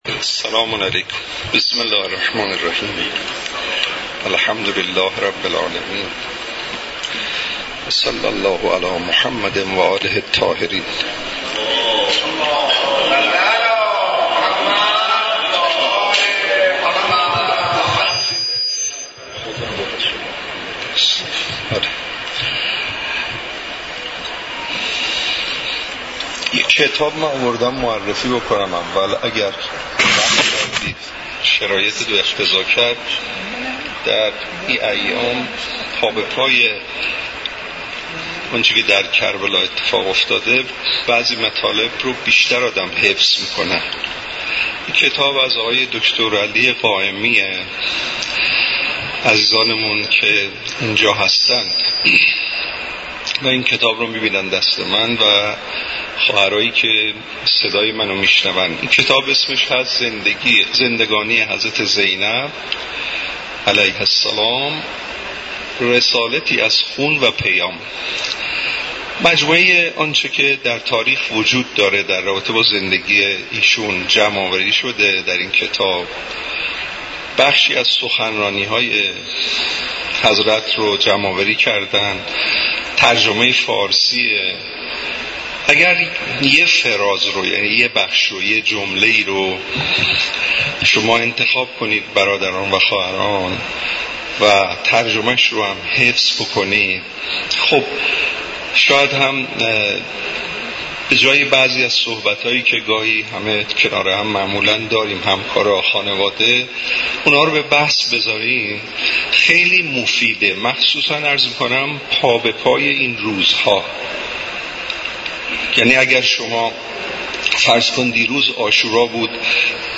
مراسم عزاداری حضرت اباعبدالله الحسین علیه السلام همراه با قرائت زیارت عاشورا ، سخنرانی و مدّاحی در دانشگاه کاشان برگزار شد.